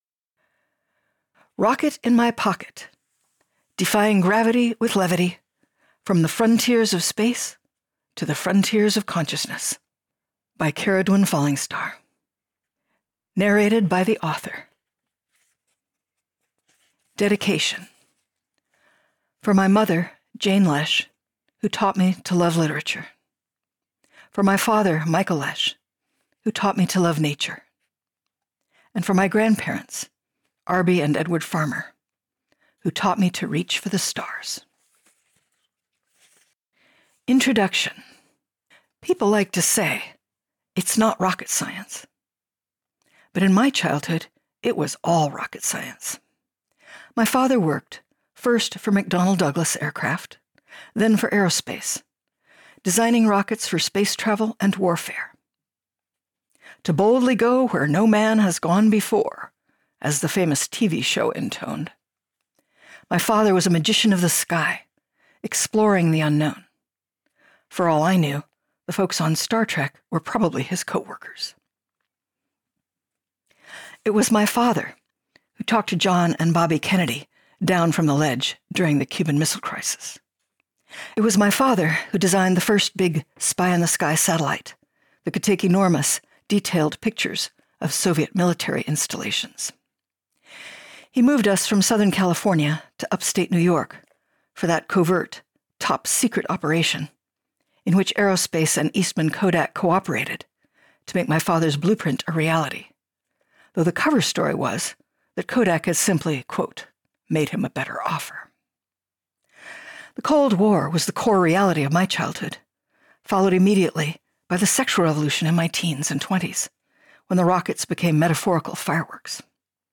Rocket In My Pocket Audiobook